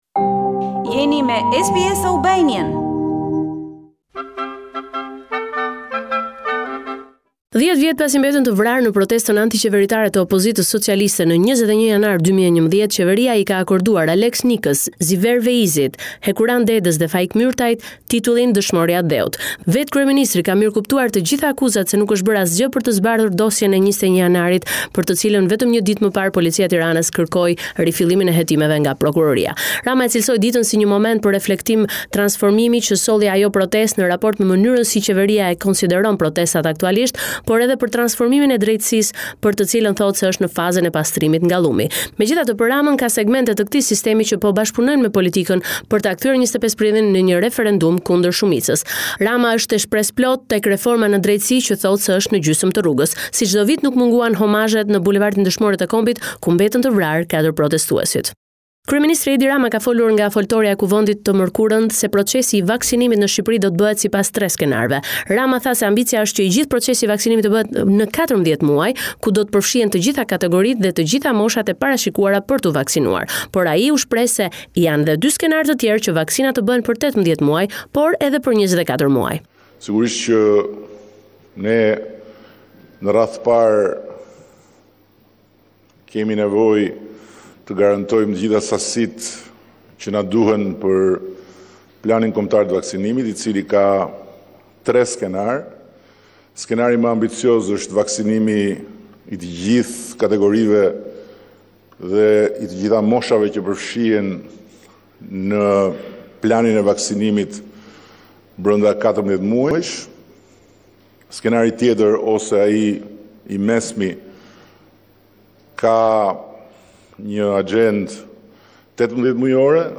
Raporti me te rejat me te fundit nga Shqiperia.